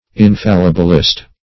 infallibilist - definition of infallibilist - synonyms, pronunciation, spelling from Free Dictionary
Search Result for " infallibilist" : The Collaborative International Dictionary of English v.0.48: Infallibilist \In*fal"li*bil*ist\, n. One who accepts or maintains the dogma of papal infallibility.